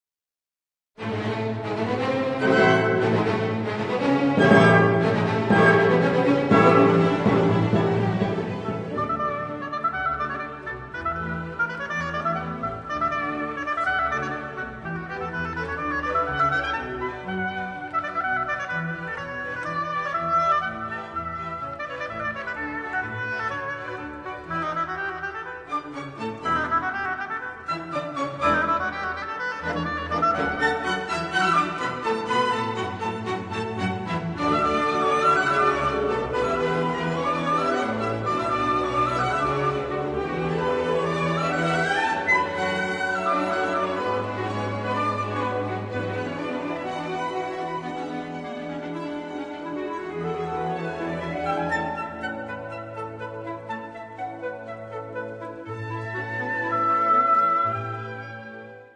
（オーボエ+ピアノ）